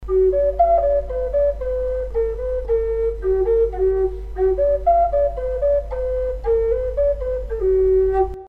Пимак G Тональность: G
Прост в исполнении, но имеет вполне достойное звучание. Изготовлен из ясеня.